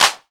Game_Snare_2.wav